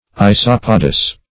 Isopodous \I*sop"o*dous\, a.